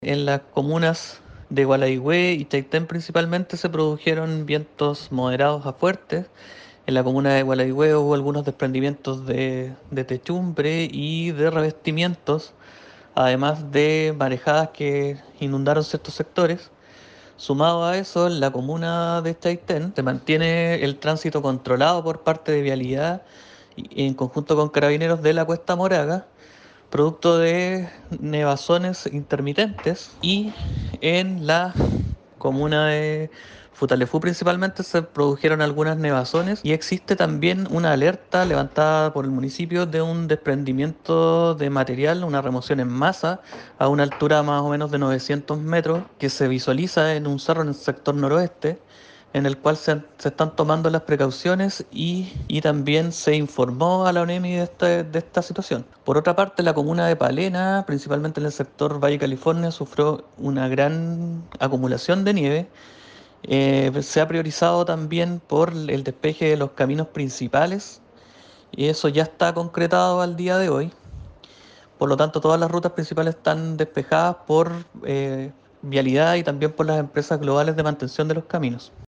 También, el delegado presidencial de Palena Luis Montaña realizó un reporte tras el frente de mal tiempo que afectó a la provincia.